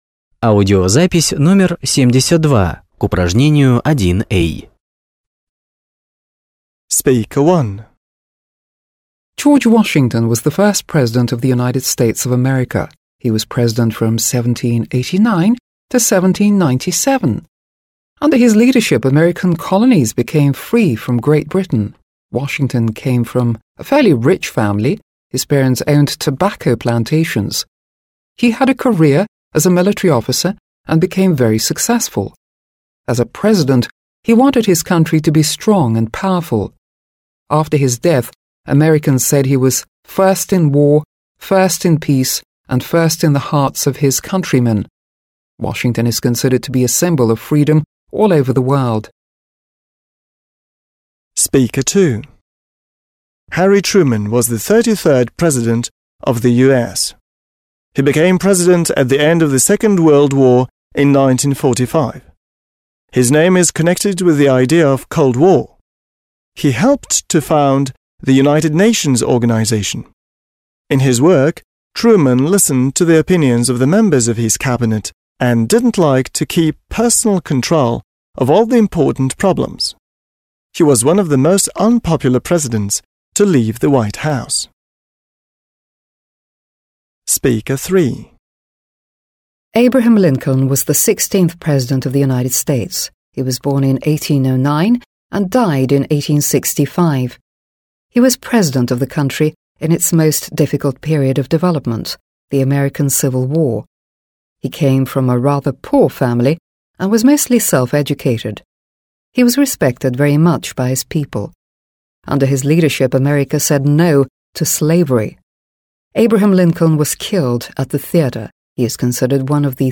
1. Послушайте четырех спикеров, которые говорят об американских президентах (1-4), (72), и сопоставьте их с утверждениями (a-e) ниже.